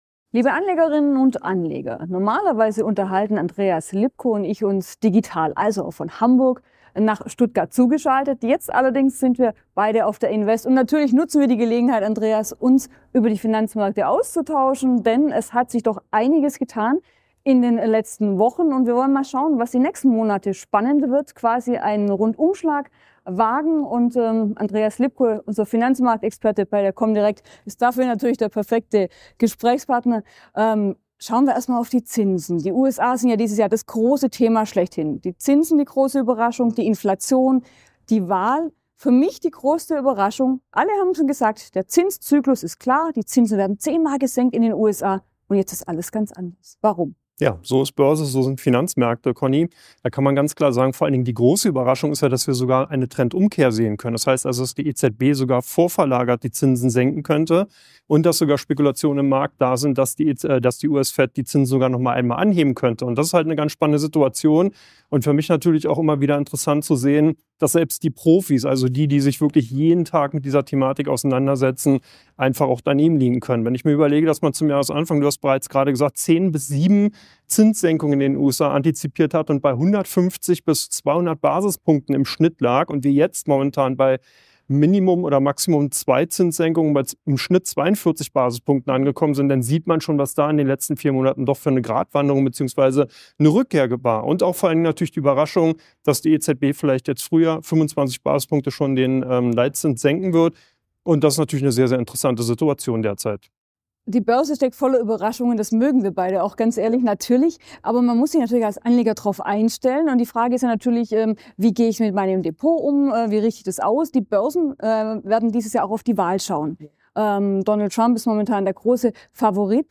Invest 2024
Ein Vortrag